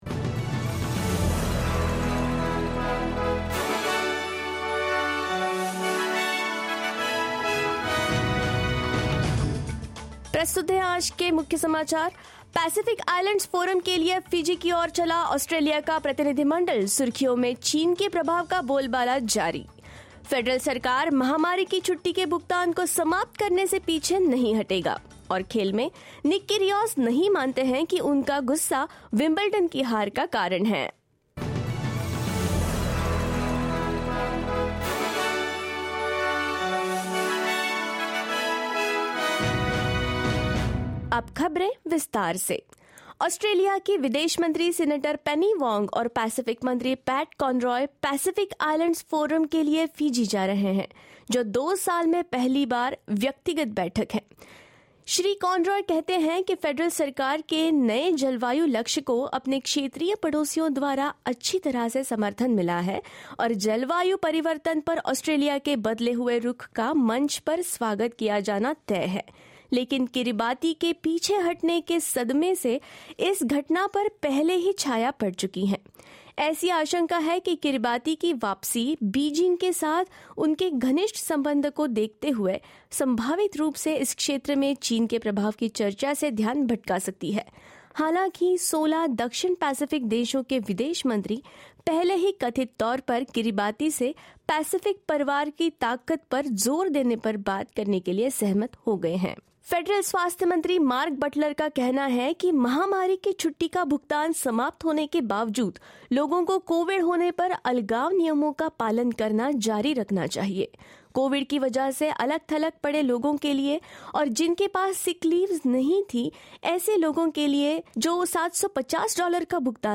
In this latest SBS Hindi bulletin: Minister for Foreign Affairs Penny Wong to attend Pacific Island Forum in Fiji; Federal Government remains firm on scrapping pandemic leave payments; Tennis star Nick Kyrgios reflects on his Wimbledon defeat and more.